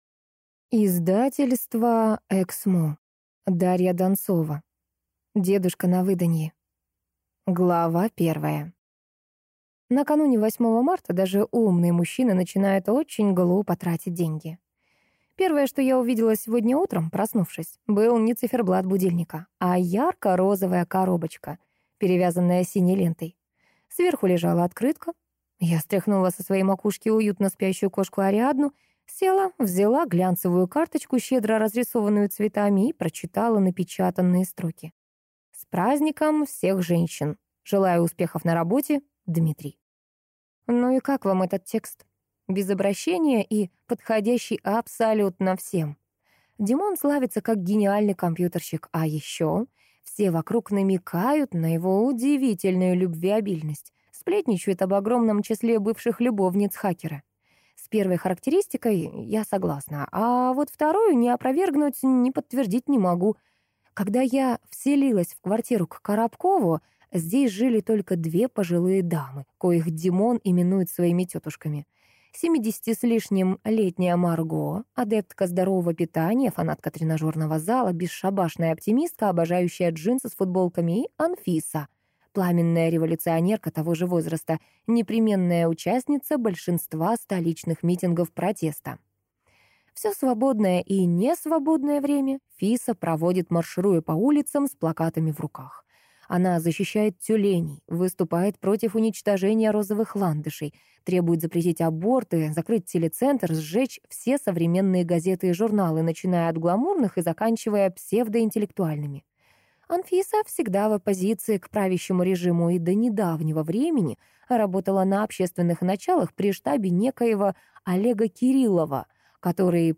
Аудиокнига Дедушка на выданье | Библиотека аудиокниг
Прослушать и бесплатно скачать фрагмент аудиокниги